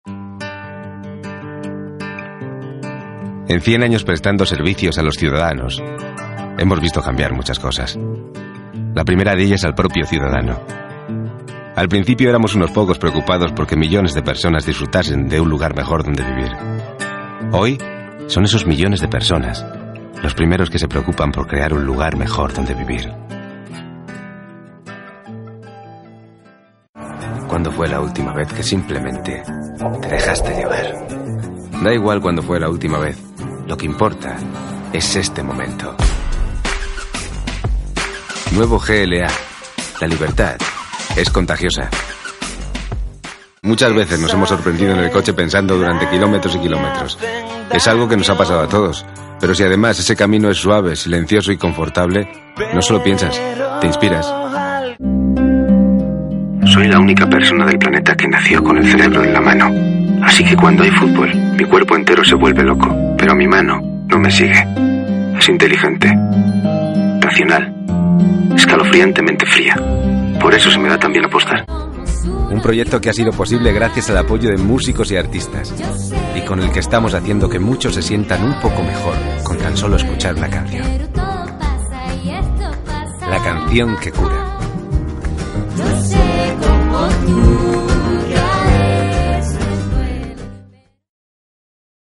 corporative, natural, close, truthful, realistic, surreal. Whatever you need. I adapt to your product.
Sprechprobe: eLearning (Muttersprache):